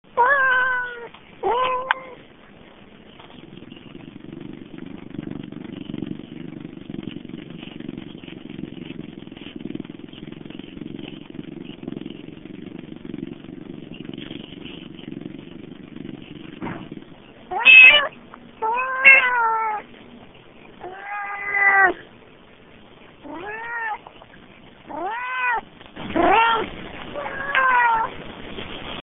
Click here to download an MP3 file so you can hear why we think he has Siamese bloodlines!